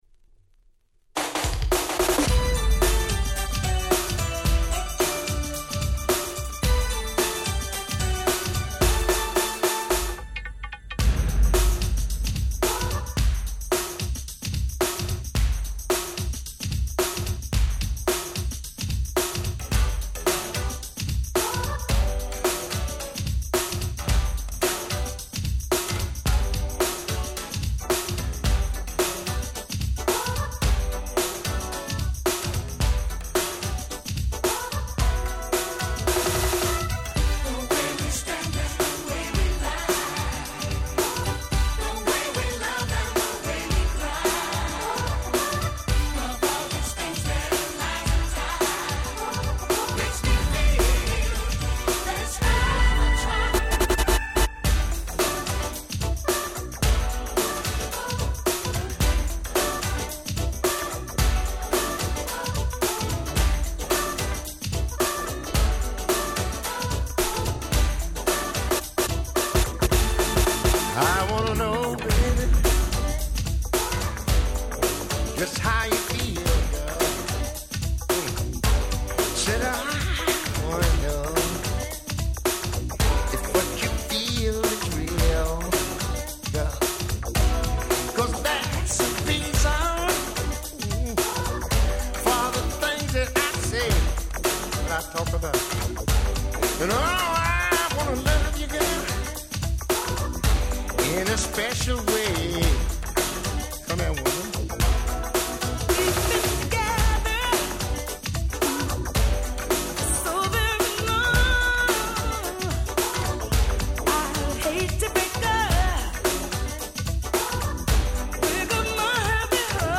89' Super Hit R&B !!